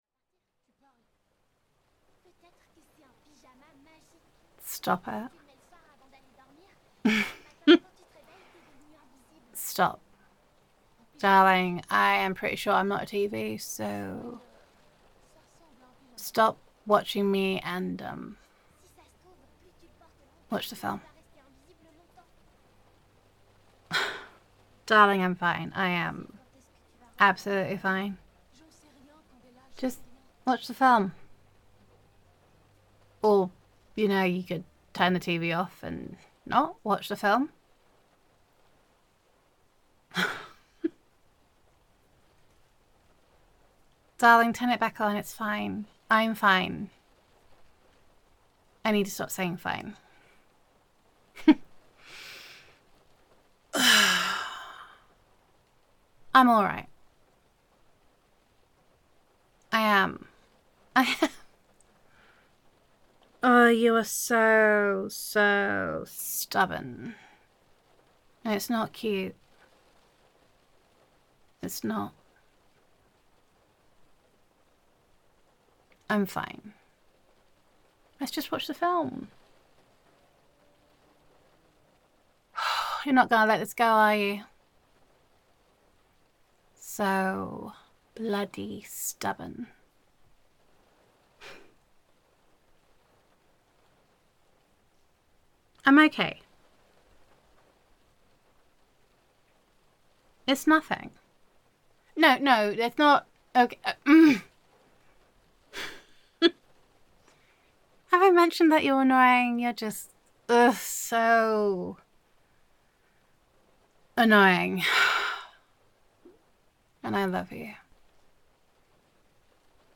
NEW REVERSE COMFORT ROLEPLAY
[F4A] You Make It Easier to Breathe